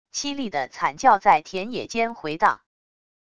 凄厉的惨叫在田野间回荡wav音频